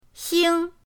xing1.mp3